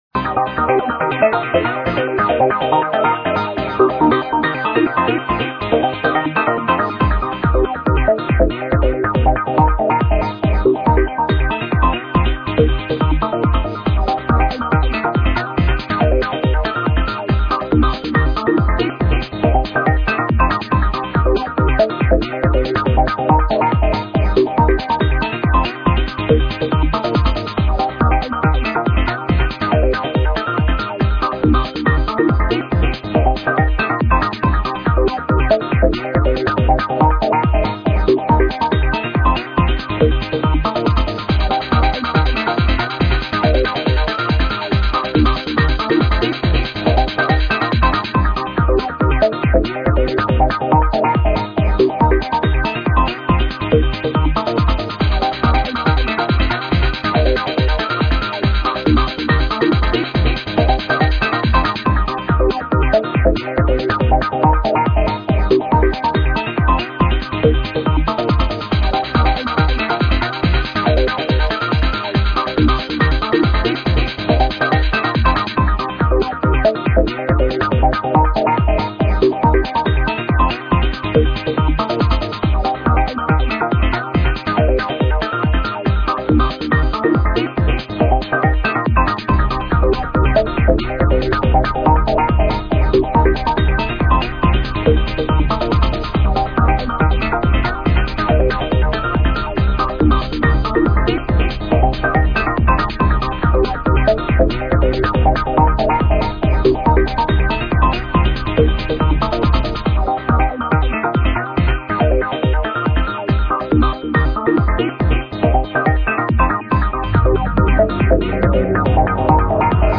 Dance
dance thang